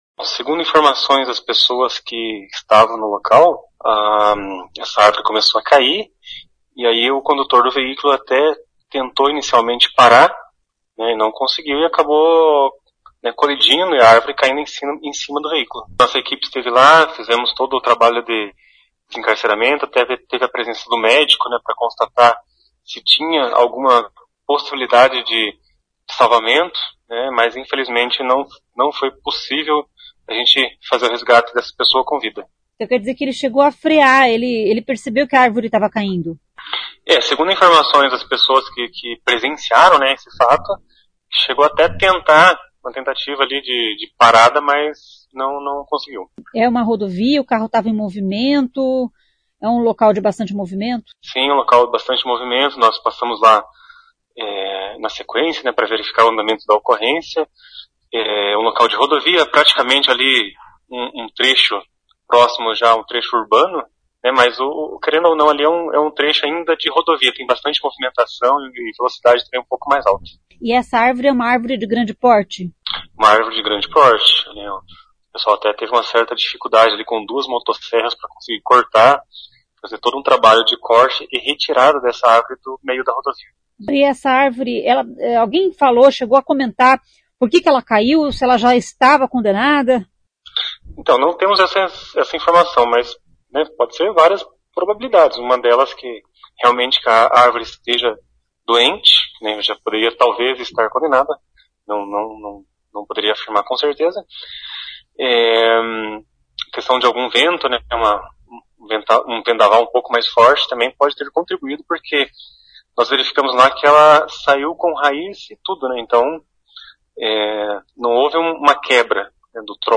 Ouça o que diz o major: